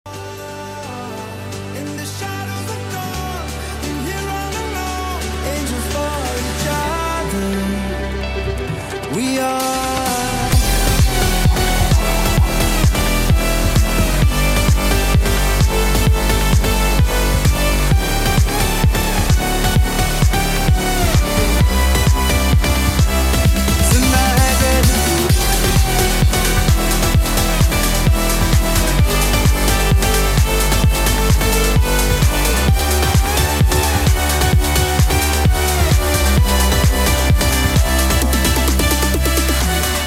רמיקס
השקעתי רק בדרופ